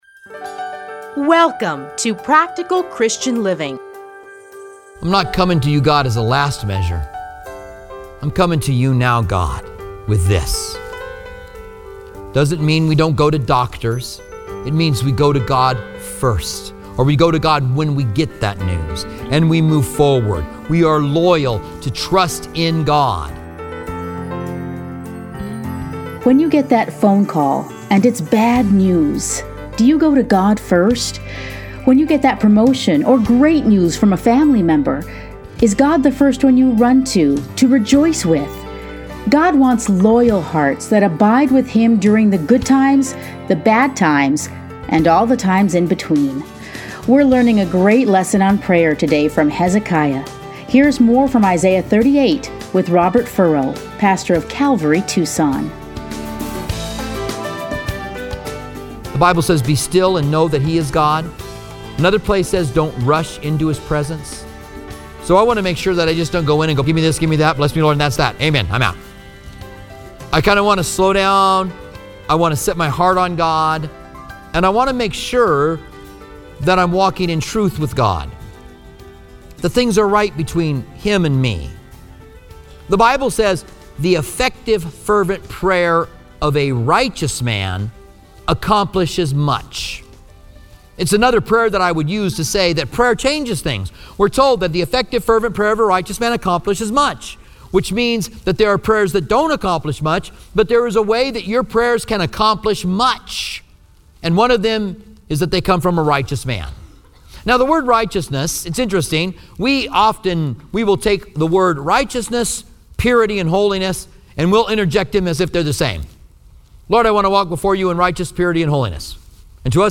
Listen here to a teaching from Isaiah.